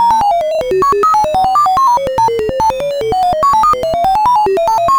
computerNoise_003.ogg